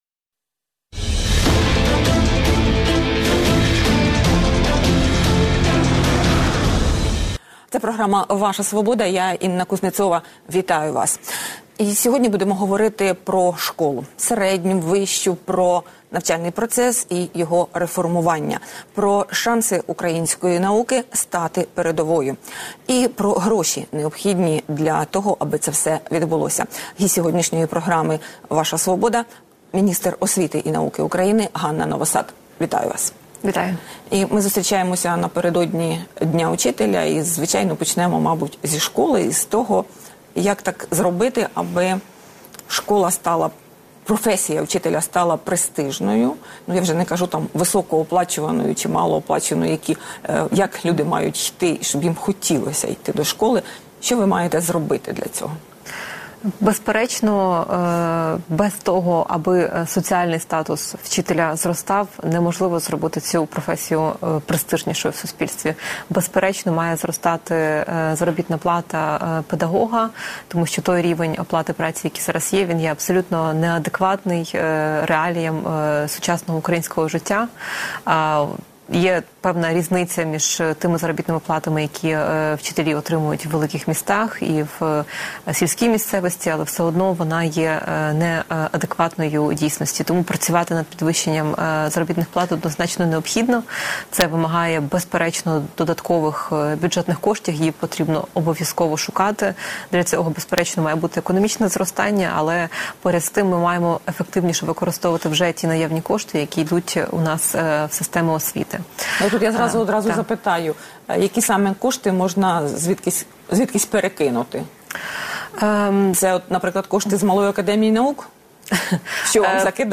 Як заплатити педагогам? Інтерв'ю з міністром освіти і науки Ганною Новосад